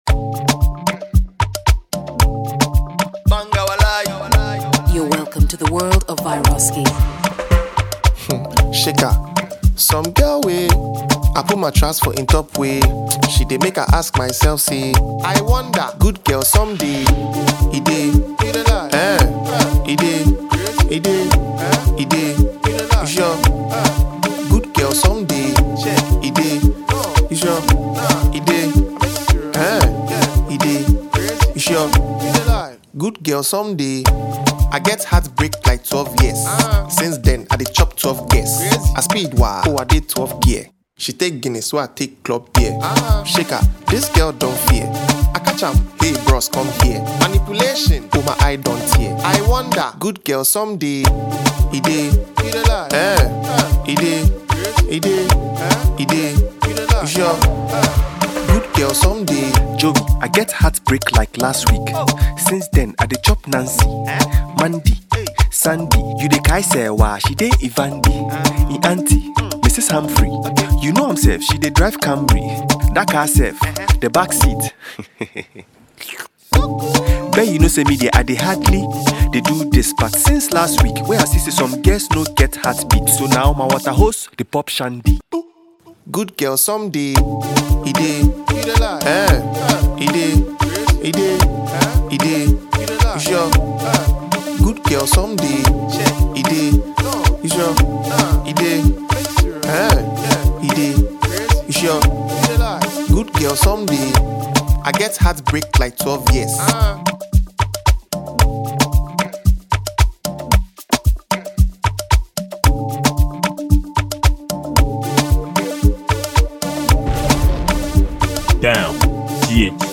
Ghana Music
This song is more likely to give you joy than sadness.